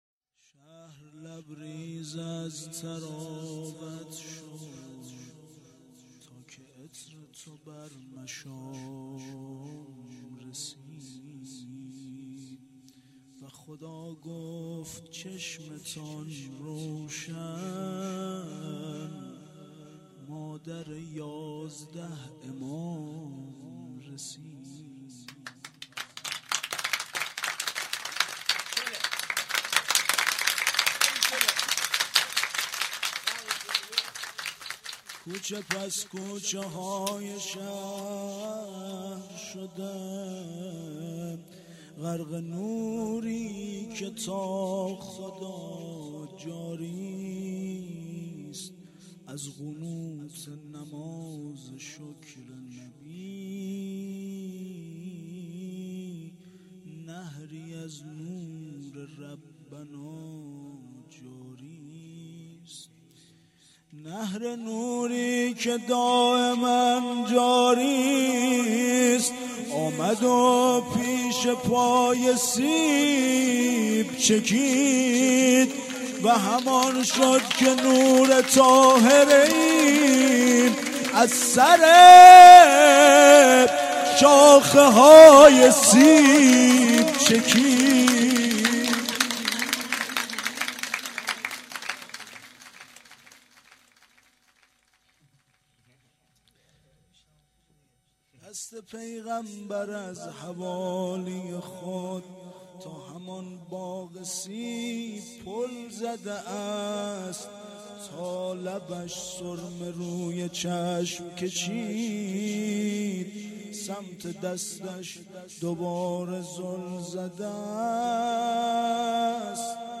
0 0 مدح
جشن ولادت حضرت زهرا(س)- جمعه 18 اسفند